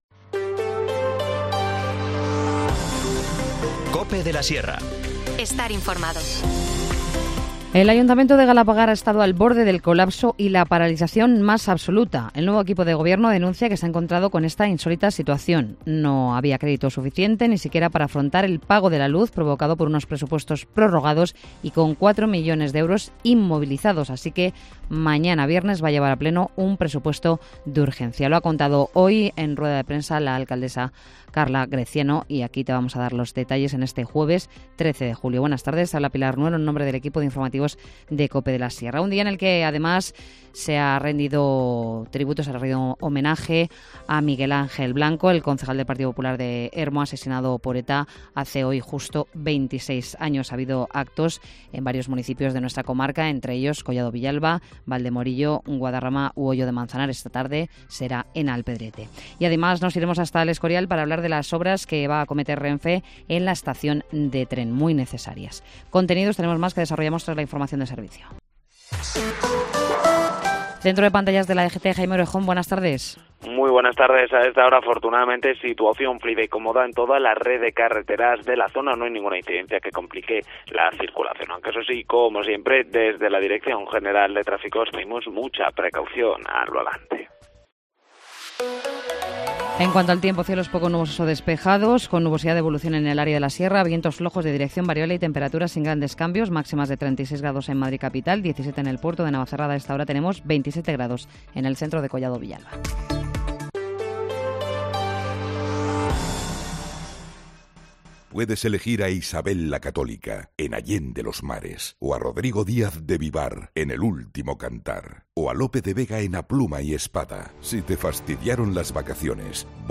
Informativo Mediodía 13 julio